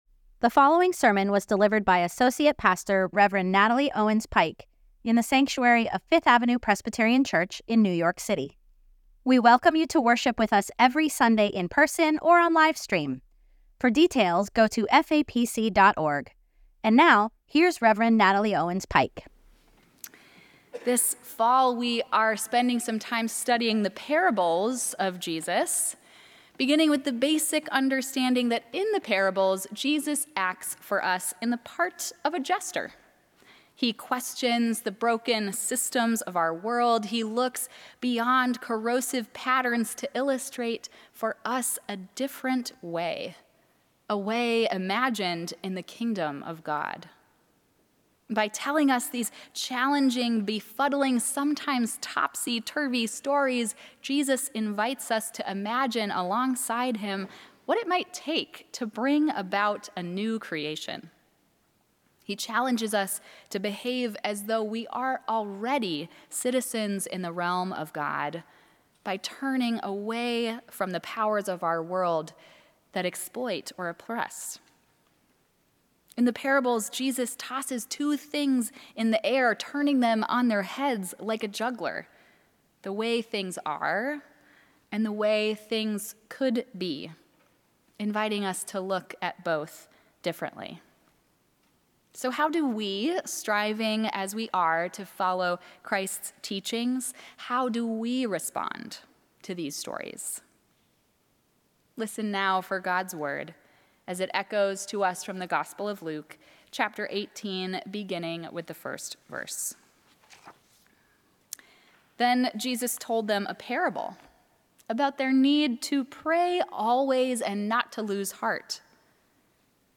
Sermon: “Justice” Scripture: Luke 18:1-8 Download sermon audio Order of Worship T hen Jesus told them a parable about their need to pray always and not to lose heart.